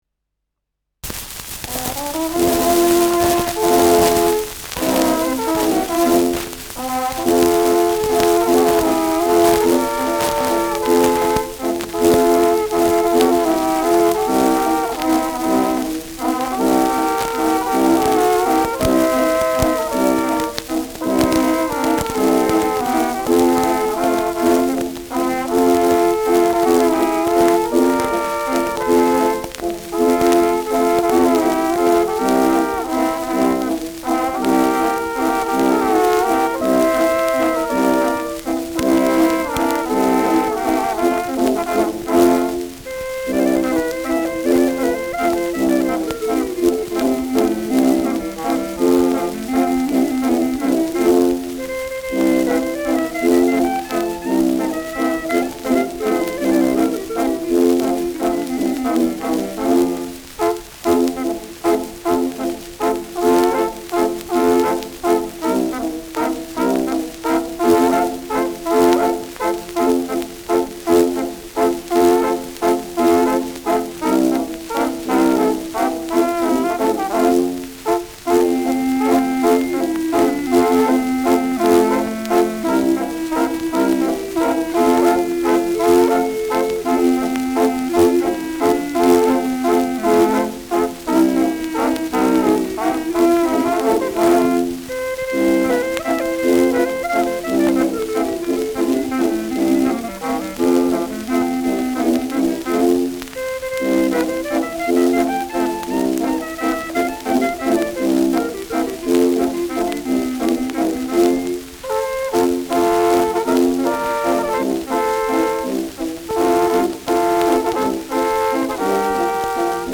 Halbwalzer : mit Trompeten-Duett
Schellackplatte
Tonrille: Kratzer 2-4 / 9 / 12 Uhr Stärker : Kratzer 12 Uhr Stark
„Hängen“ im Schlussakkord : präsentes Rauschen
Dachauer Bauernkapelle (Interpretation)